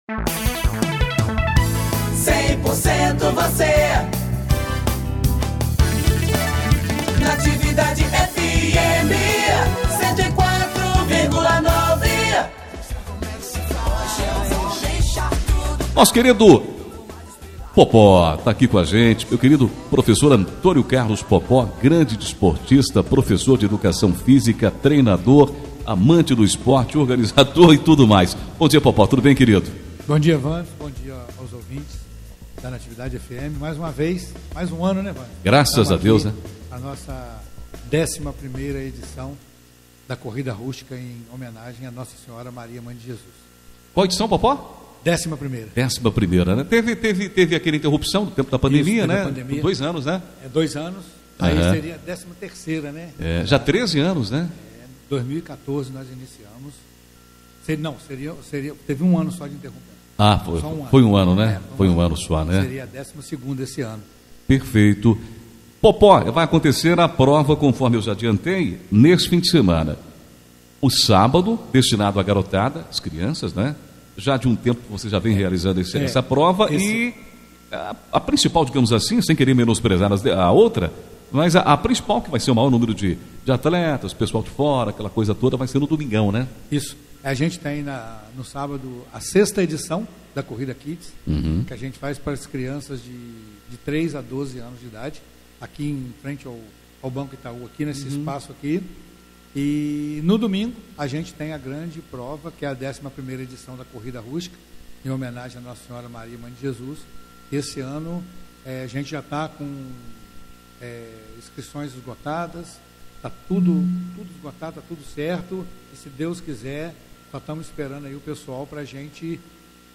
9 de dezembro de 2025 DESTAQUE, ENTREVISTAS